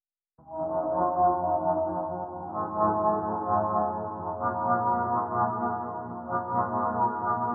synthe_srx_studio_01.wav